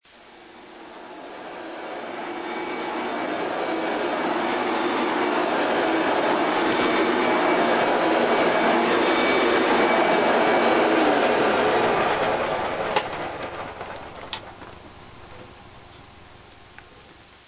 vacuumnoise.mp3